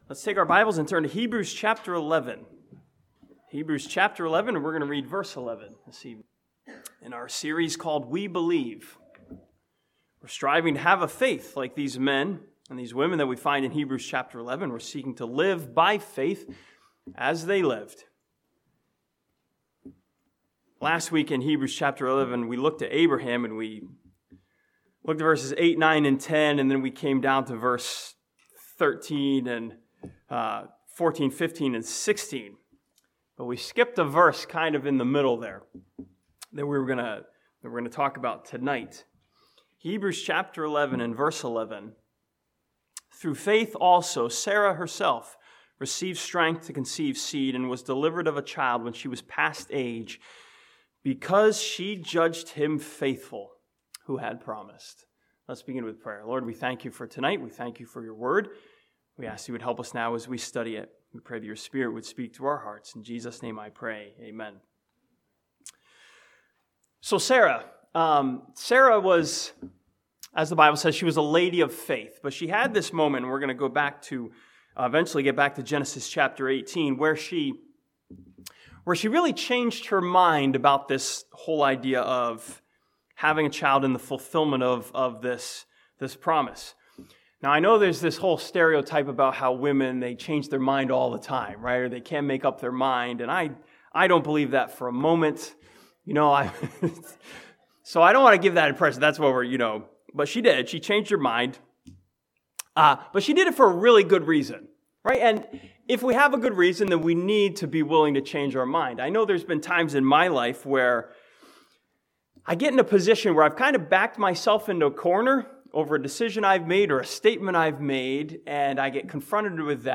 This sermon from Hebrews 11:11 studies the change in Sarah's faith from doubt to faith in her trustworthy God.